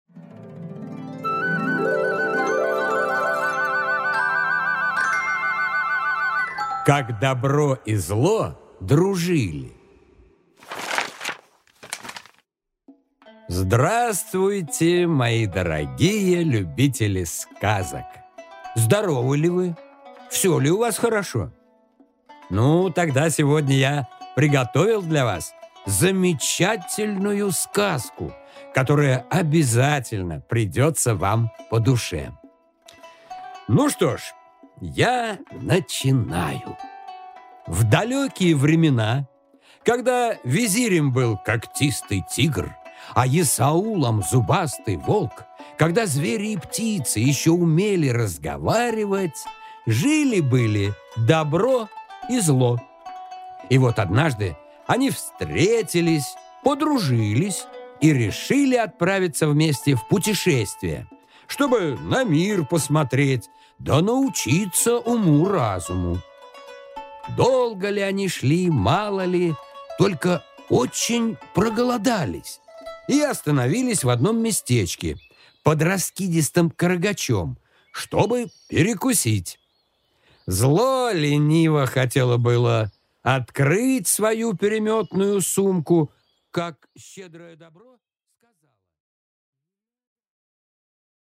Аудиокнига Как Добро и Зло дружили | Библиотека аудиокниг